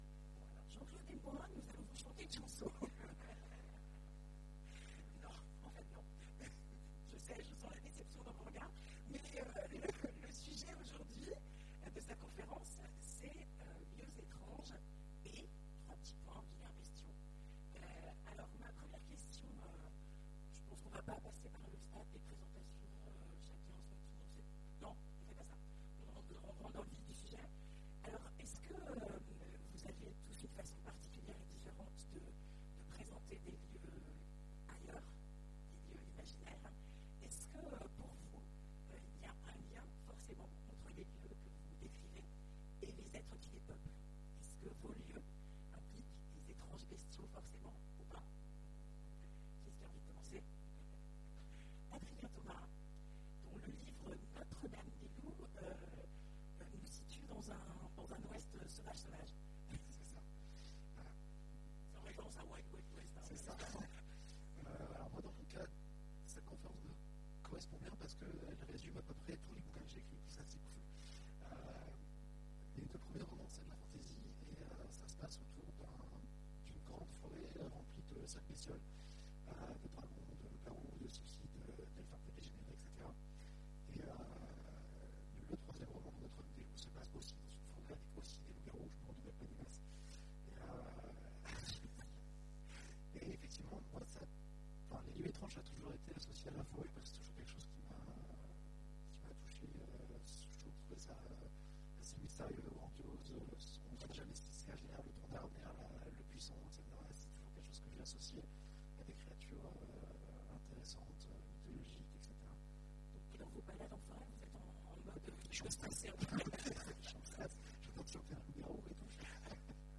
Imaginales 2015 : Conférence Lieux étranges et vilains bestiaux